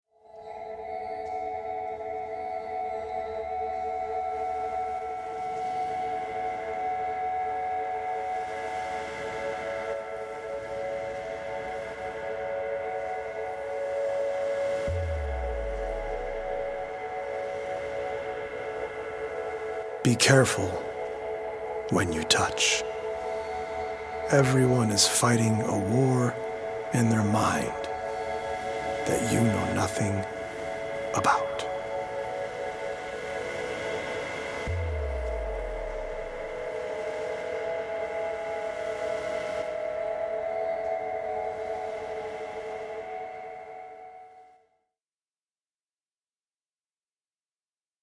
healing audio-visual poetic journey
healing Solfeggio frequency music